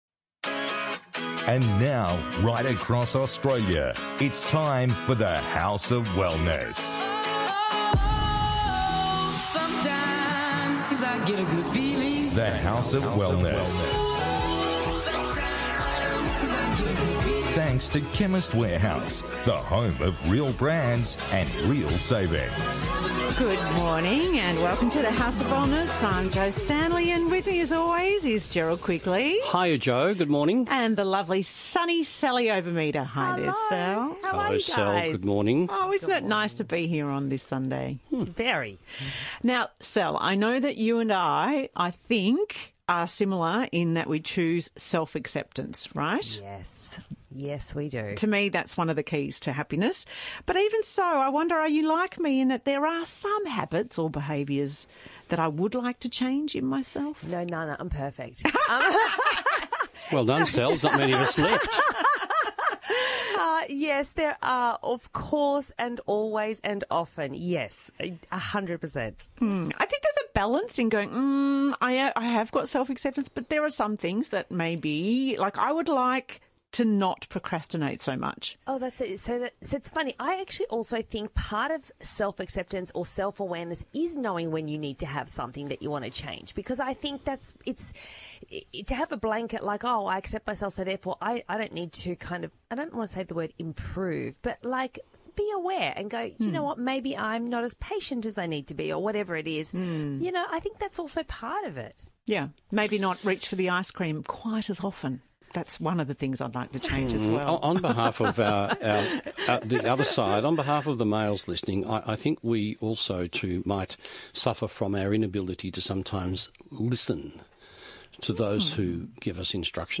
On this week’s The House of Wellness radio show the team discusses: